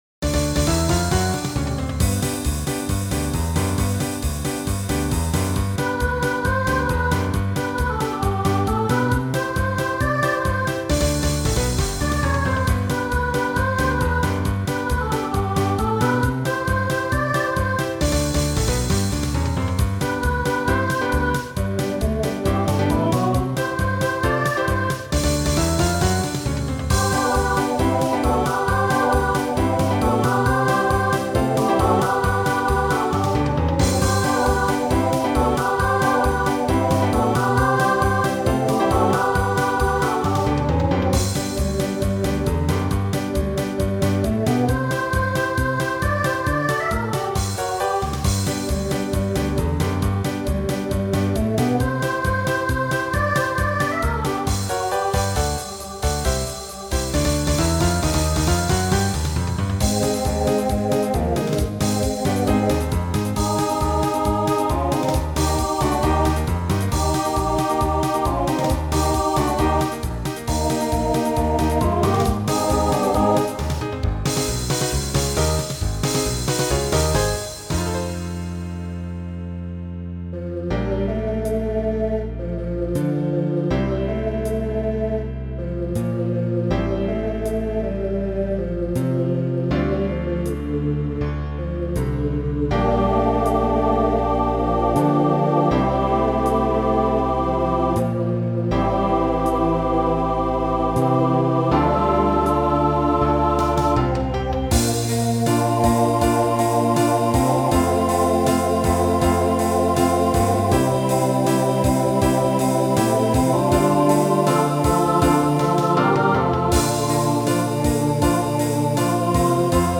Voicing SATB Instrumental combo Genre Country , Pop/Dance